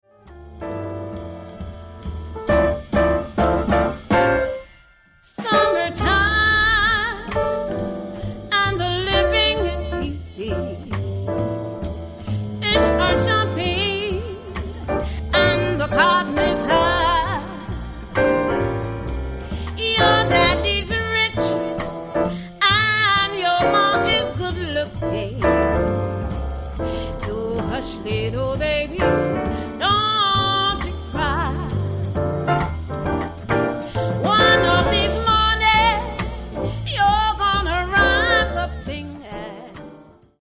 creates a sophisticated elegant atmosphere at any reception.